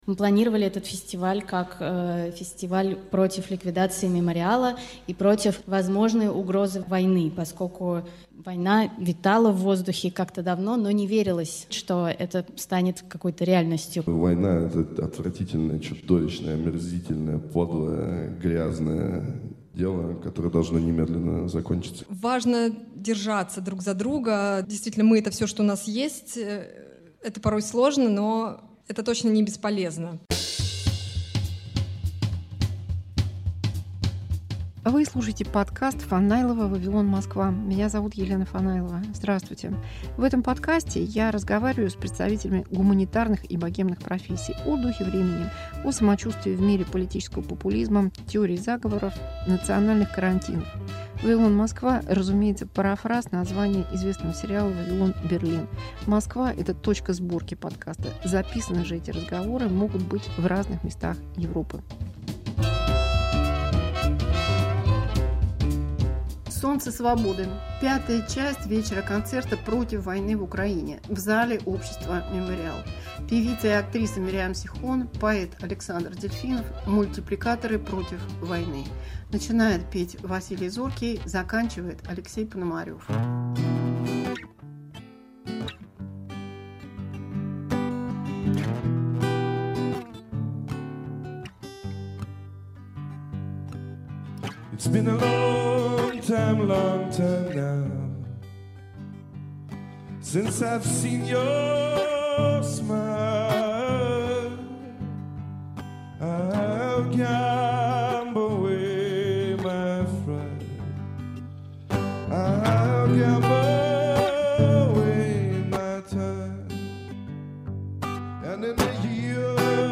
Антивоенный вечер в "Мемориале", часть 5.